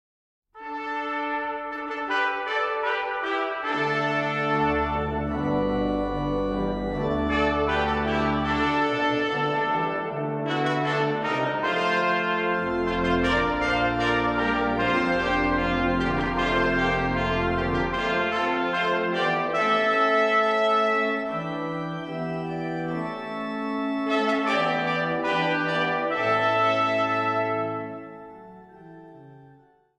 uit de Westerkerk te Ermelo